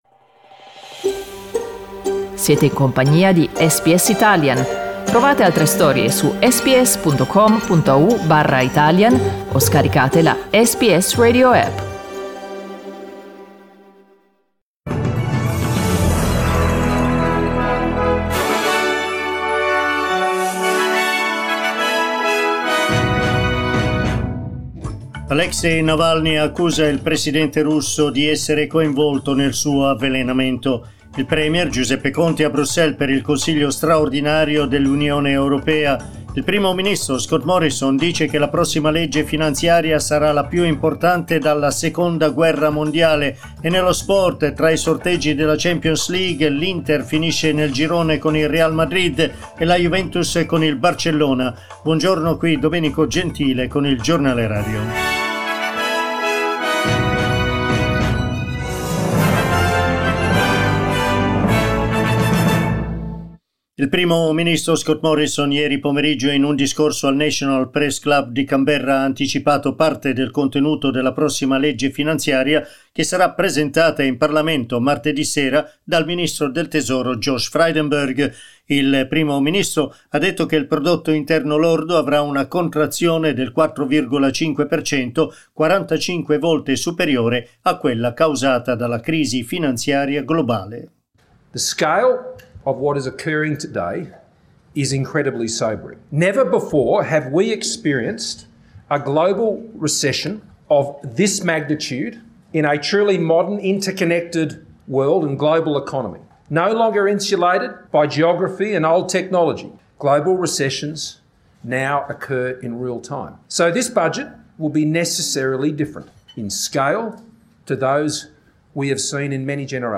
News bulletin in Italian broadcast this morning at 09:00am.